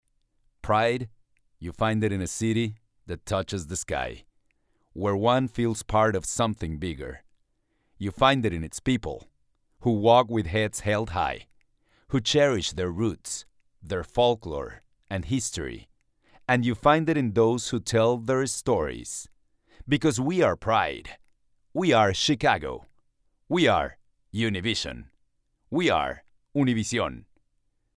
Male
Adult (30-50)
My style could be corporate, friendly, warm, authoritative, dry, breathy and elegant.Have 30 years + experience in commercials, corporate and training videos, IVR's, E-Learning, characters, etc.
Latin Accented English
All our voice actors have professional broadcast quality recording studios.
0220Demo_Accented_English.mp3